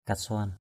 /ka-sʊa:n/ (cv.) kasuan ks&N (d.) đồng thòa = alliage de cuivre et d’or. alloy of copper and gold. karah kasuan krH kx&N nhẫn bằng đồng thòa. ring which is made from alloy...
kasuan.mp3